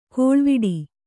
♪ kōḷviḍi